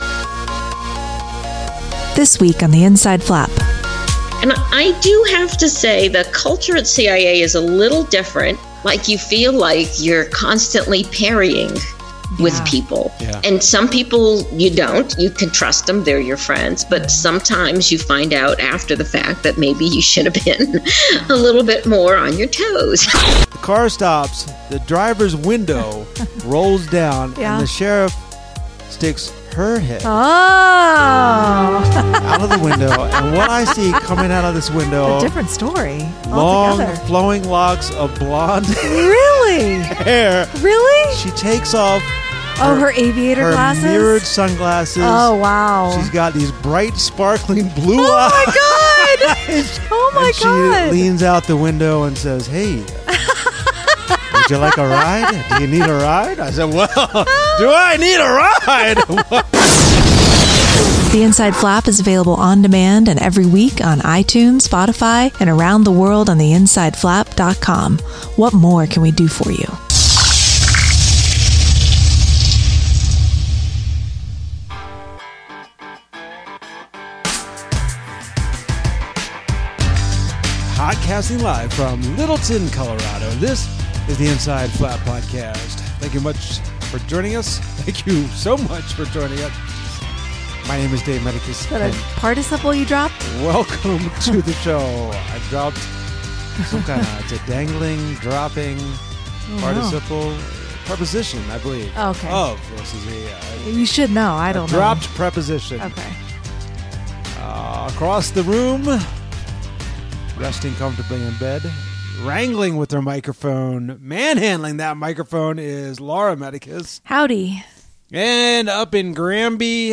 Today we're joined by the talented novelist Alma Katsu for a chat about her new novel Red Widow, women in the CIA, trust and mistrust in the agency, constantly being watched by counter intelligence, code names, and the automated hot dog machine in the bowels of the CIA building.